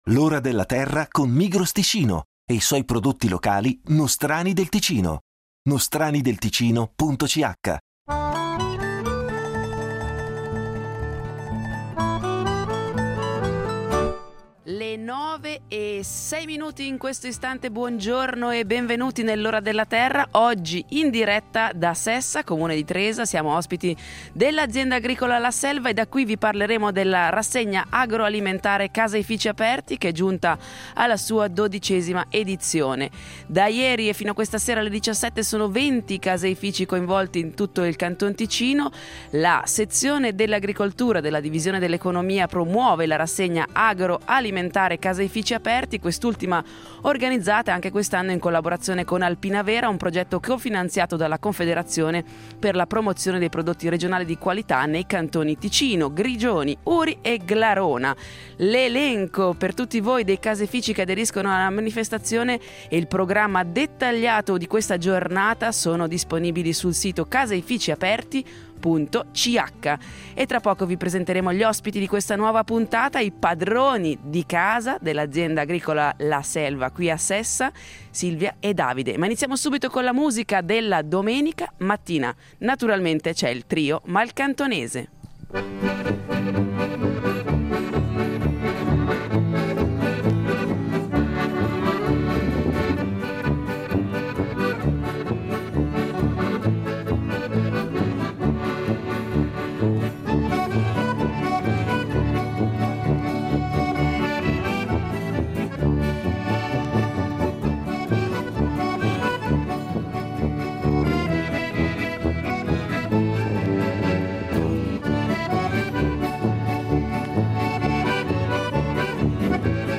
L’Ora della Terra sarà in diretta da Sessa, Comune di Tresa. Ospiti dell’Azienda agricola La Selva, vi racconteremo della rassegna agroalimentare Caseifici Aperti , che è giunta alla sua dodicesima edizione. 20 sono i caseifici coinvolti in tutto il Canton Ticino in questo 2025.